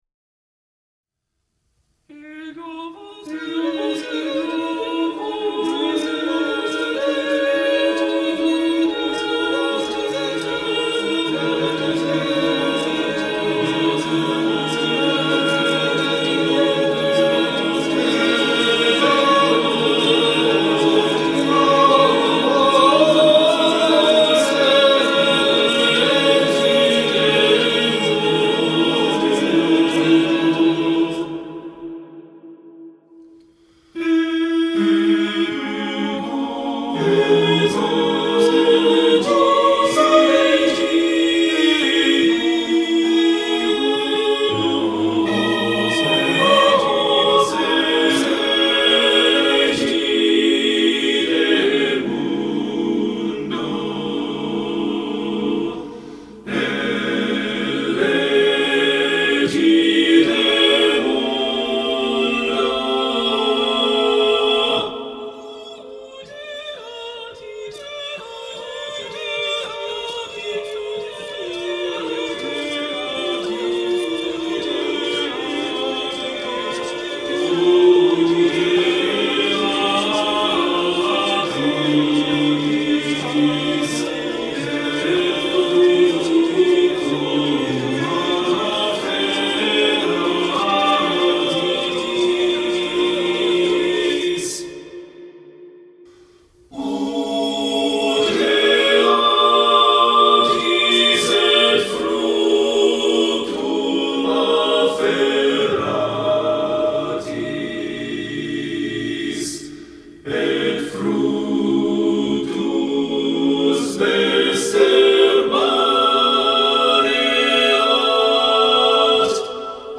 Keyboard reduction may be used for rehearsal or performance as desired.